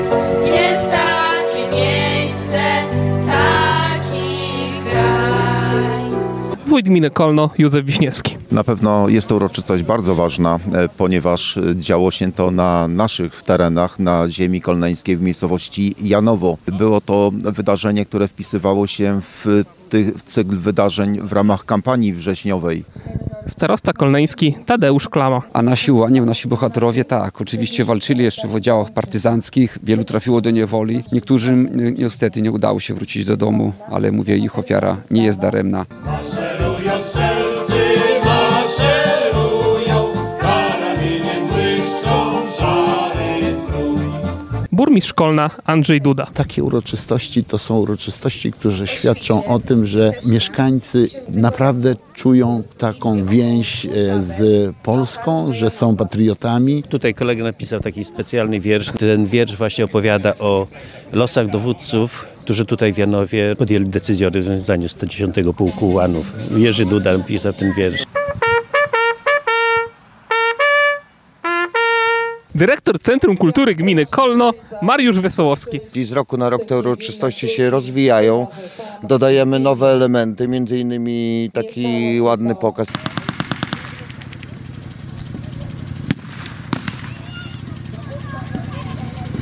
W 86. rocznicę rozwiązania pułku (28.09) tłumy patriotów zgromadziły się w Janowie, by uczcić pamięć bohaterów.
Więcej o obchodach w Janowie w naszej relacji: